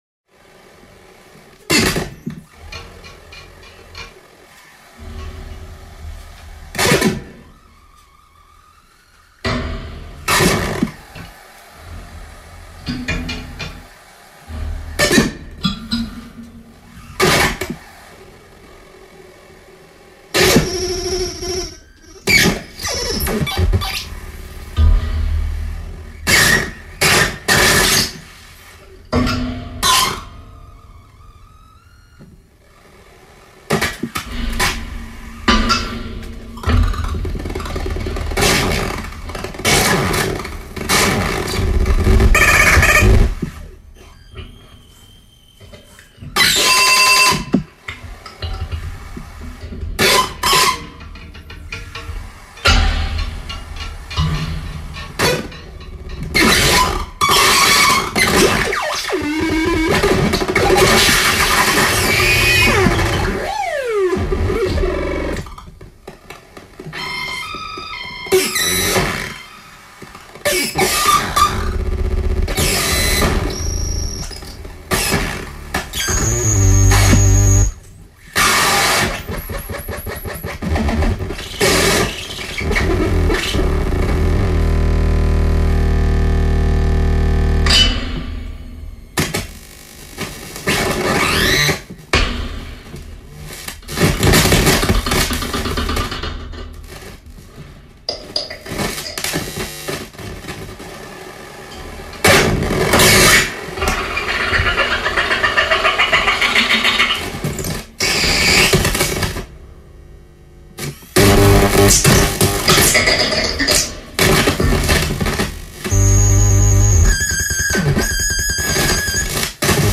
そこには、全く新しい音のバランスが現れている。
二人で演奏しているのに手が四本ある個人が演奏しているようにも錯覚してしまう。